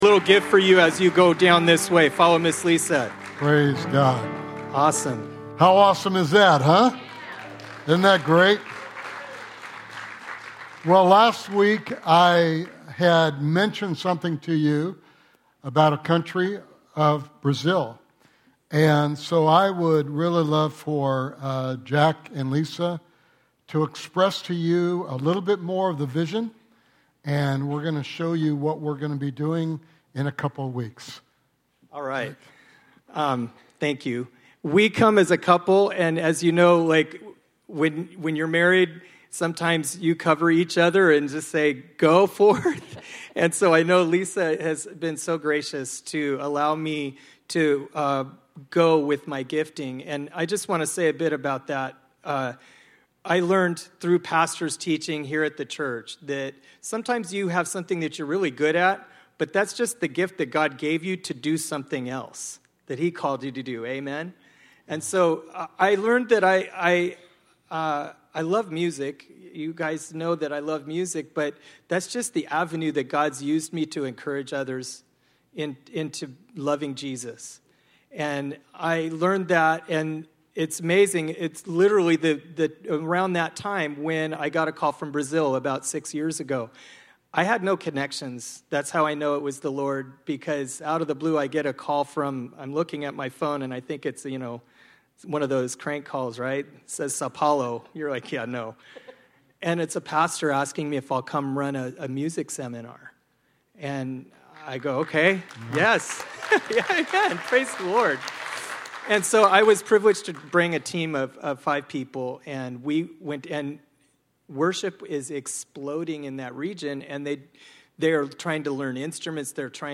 Sermon Series: The Importance of Relationship Sunday morning sermon